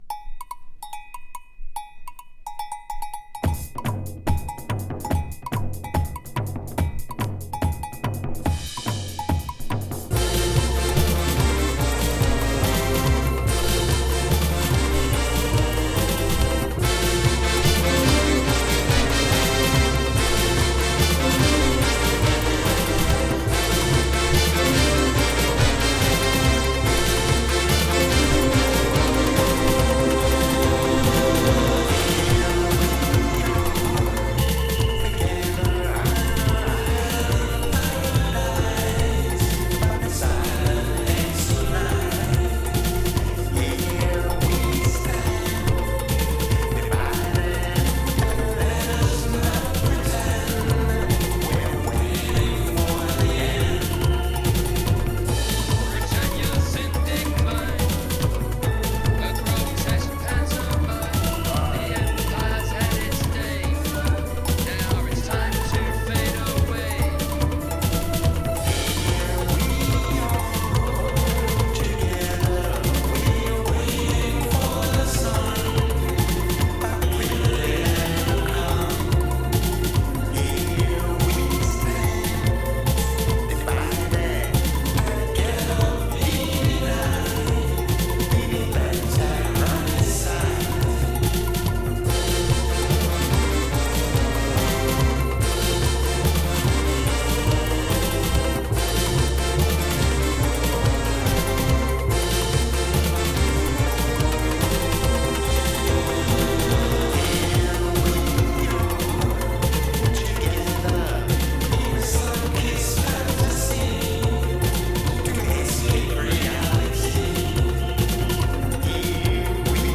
Ethno & Dubby New Wave Funk！
【NEW WAVE】【DUB】【DISCO】